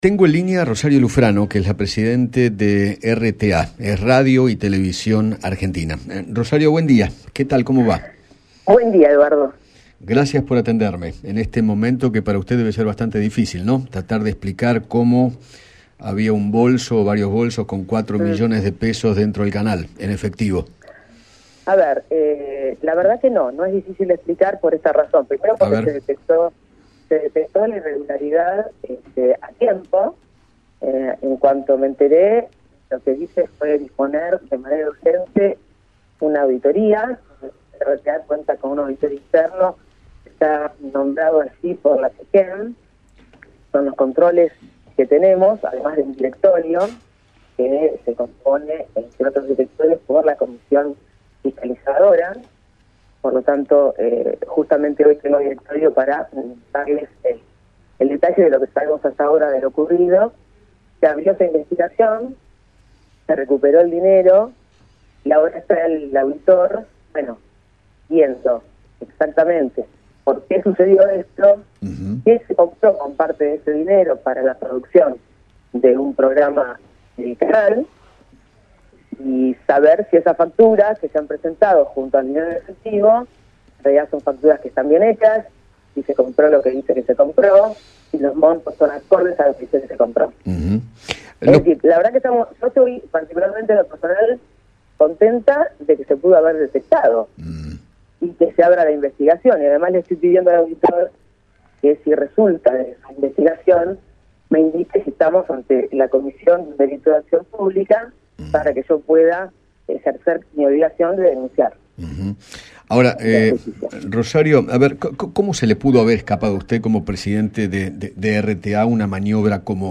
Rosario Lufrano, presidenta de Radio y Televisión Argentina, dialogó con Eduardo Feinmann sobre la polémica que se generó por una gran suma de dinero que se retiró de la cuenta bancaria del canal, utilizada supuestamente para cubrir los gastos de una miniserie de Manuel Belgrano, y que terminó con una auditoría interna.